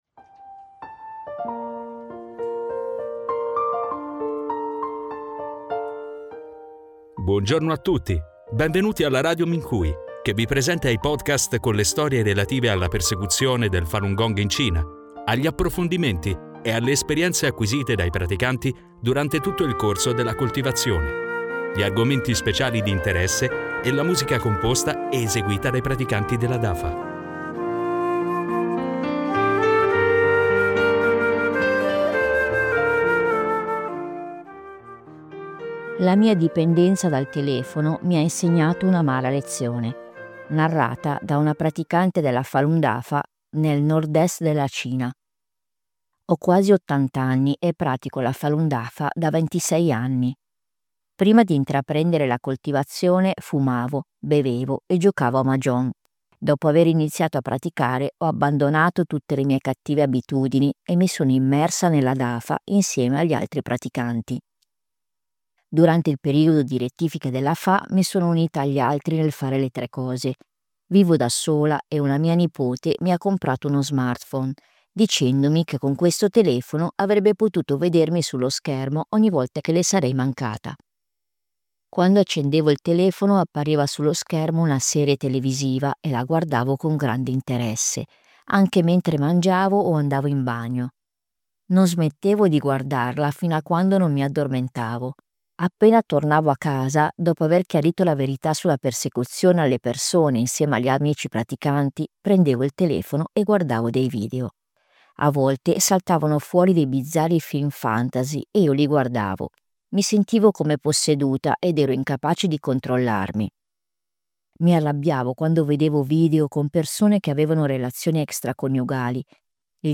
Categoria: Podcast: Esperienze di coltivazione